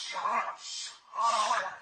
白鹭鸟叫声